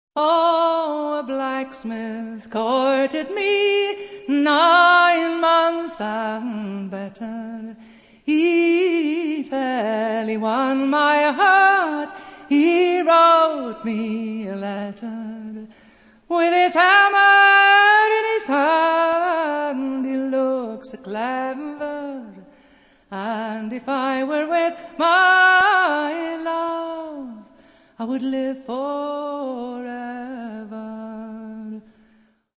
First part, 0:32 sec, mono, 22 Khz, file size: 128 Kb.